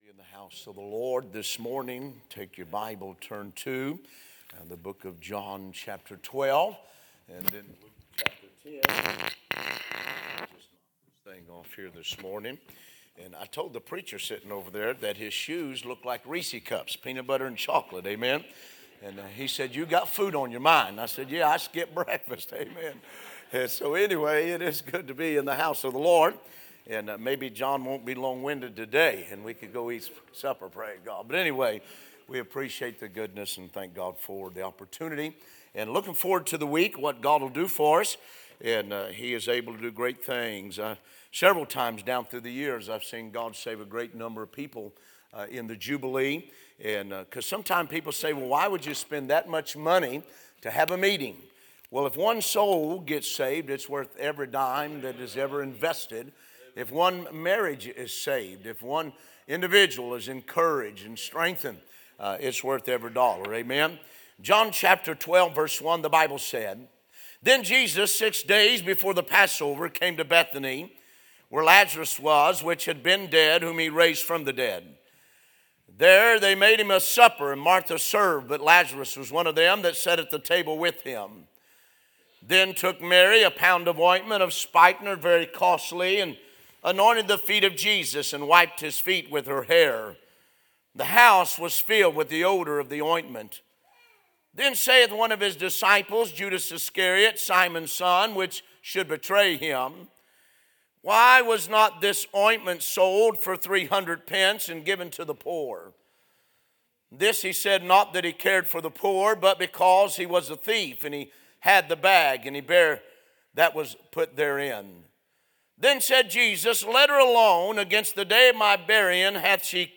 A sermon preached Sunday Morning during our Spring Jubilee, on March 23, 2025.